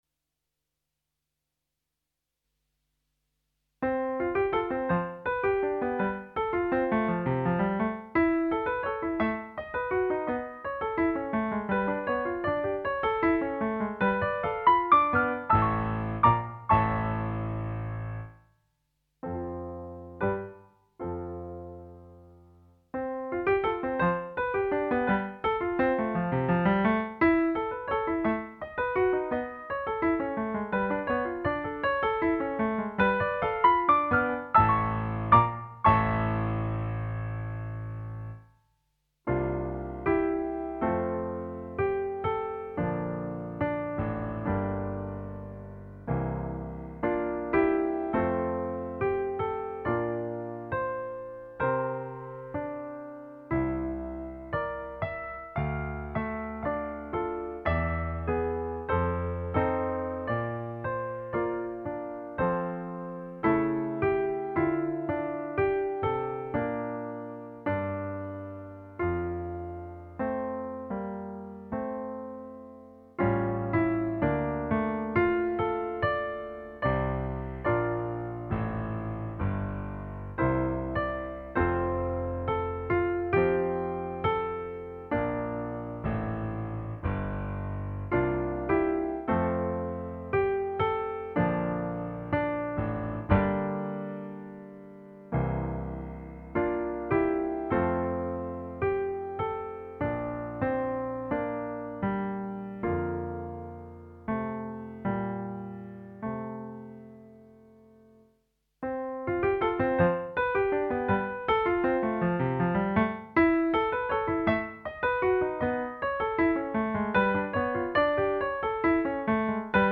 Instrumentation:Piano Solo
These short pieces are written in the romantic tradition